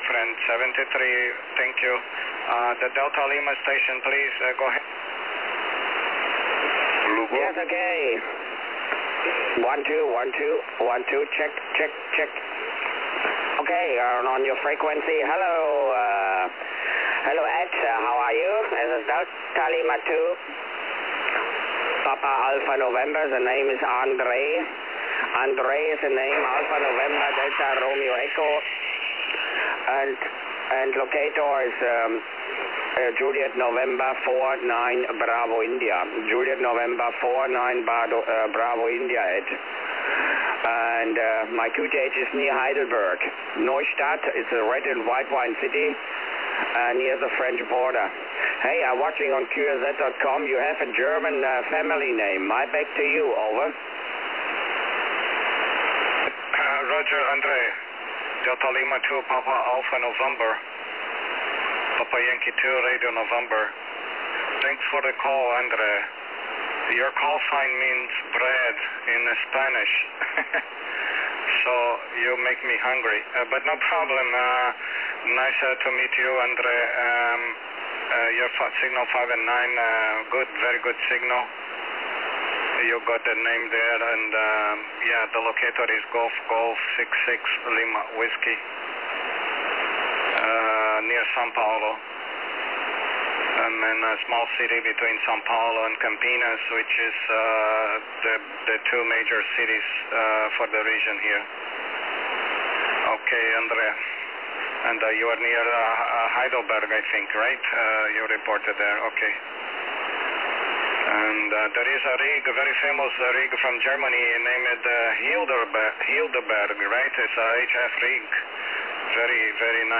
QSO